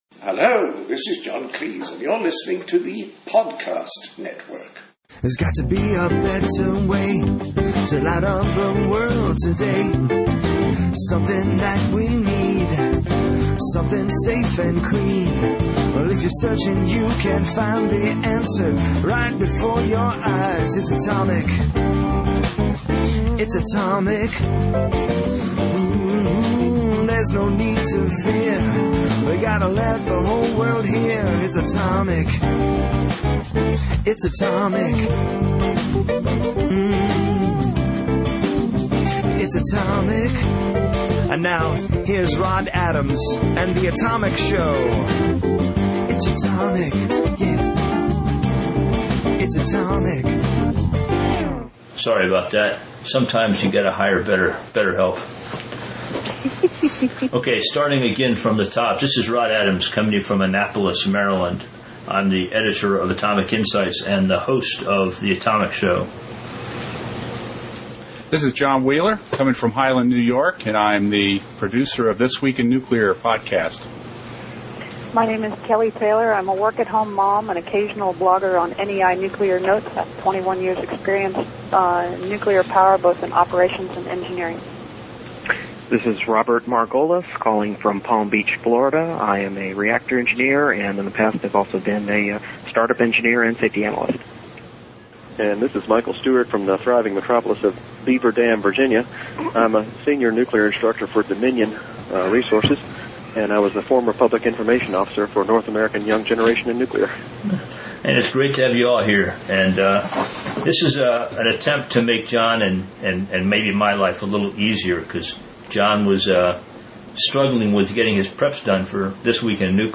First of all, I apologize for the sound quality. I goofed something up with the settings this week and could not make enough adjustments in post production to fix the strange background noises and echoing voices.